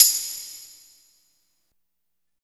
62 TAMB   -L.wav